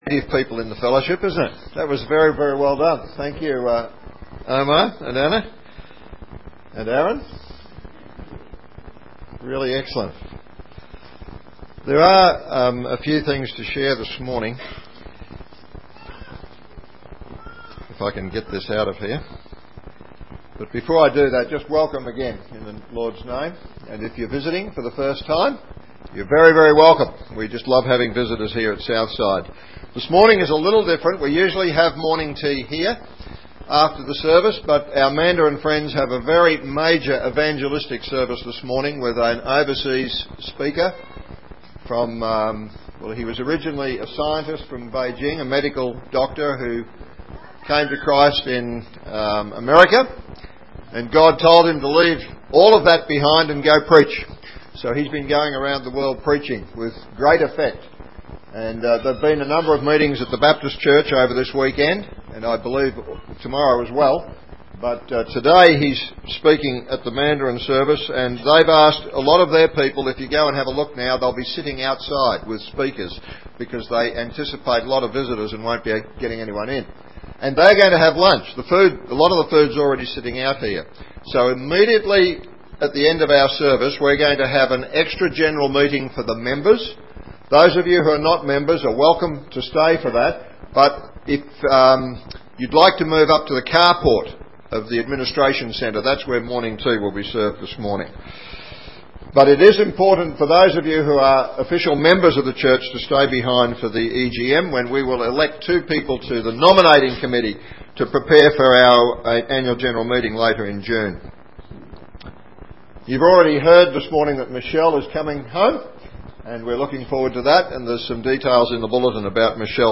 Ephesians Listen to the sermon here.